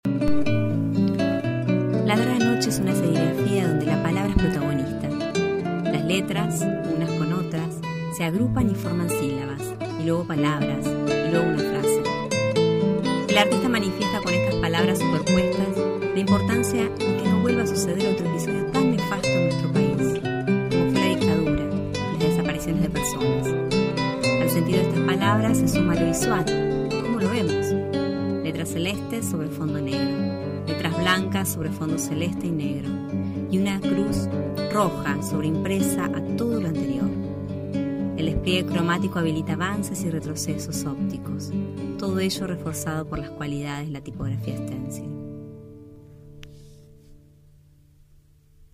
Audioguía adultos